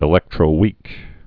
(ĭ-lĕktrō-wēk)